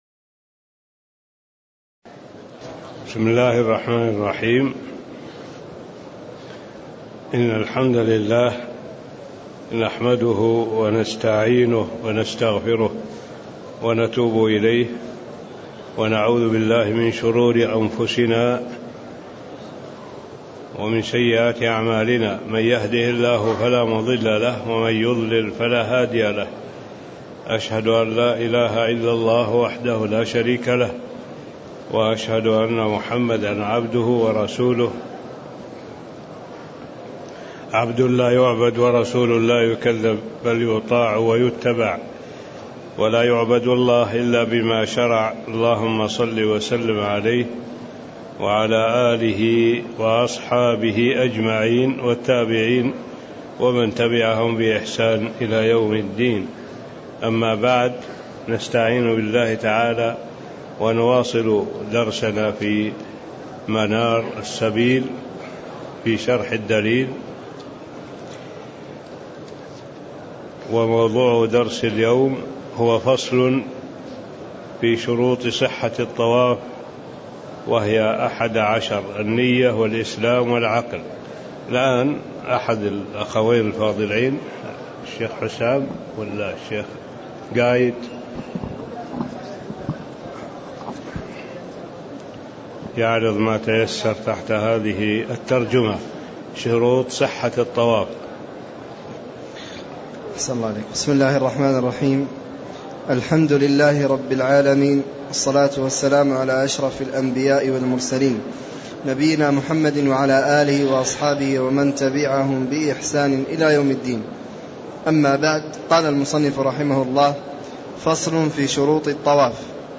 تاريخ النشر ١٦ ذو القعدة ١٤٣٦ هـ المكان: المسجد النبوي الشيخ